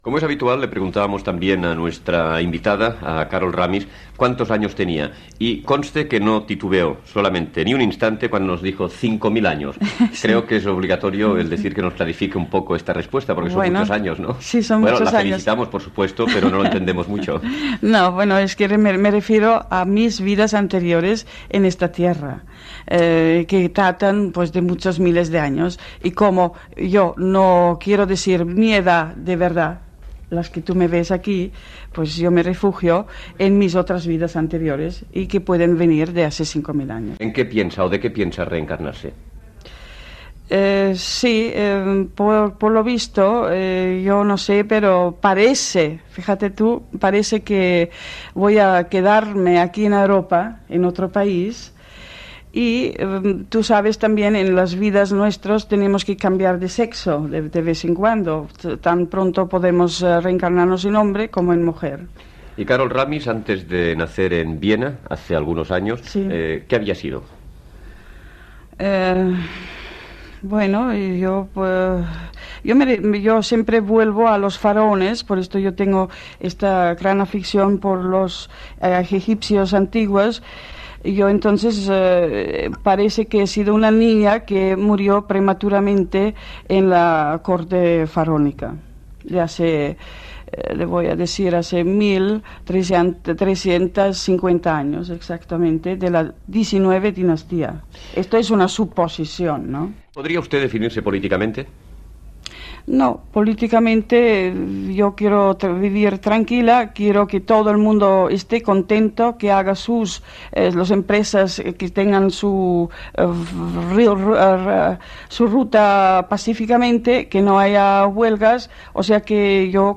Preguntes a la invitada.
Entreteniment